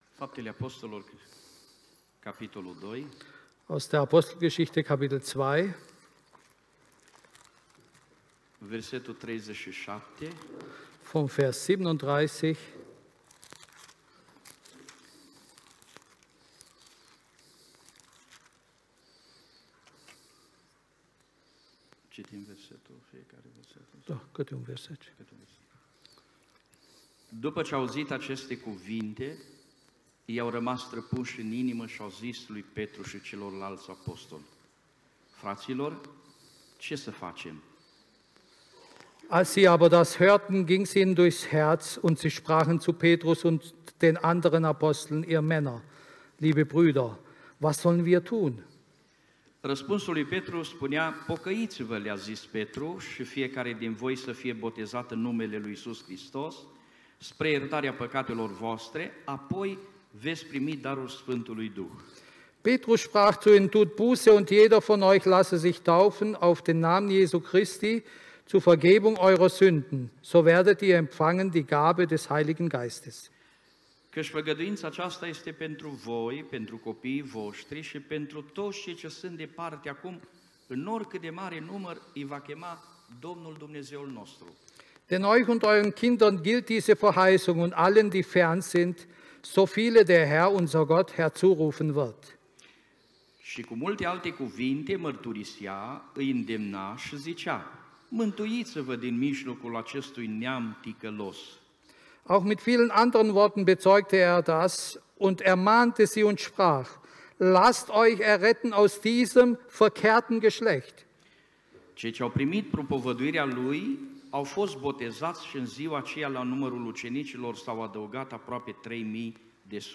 Predigt
im Christlichen Zentrum Villingen-Schwenningen. - Sprache: Rumänisch mit deutscher Übersetzung